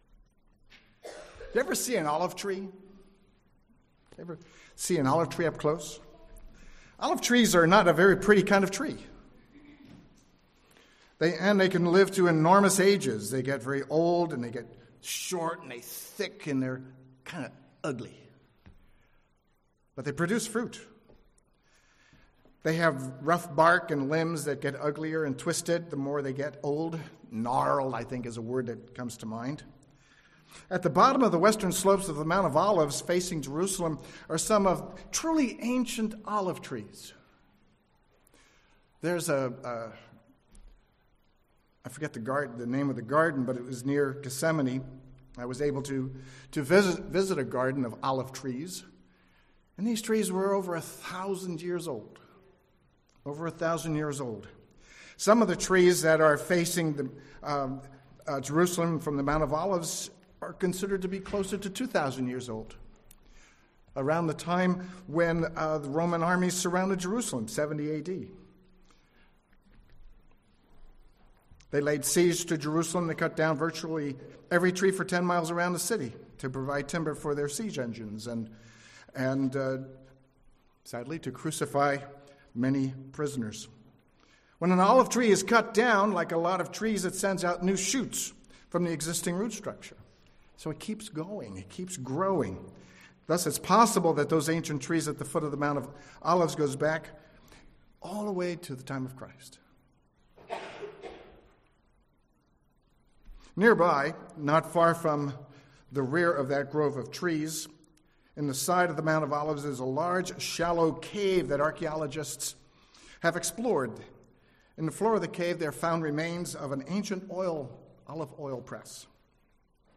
Given in San Jose, CA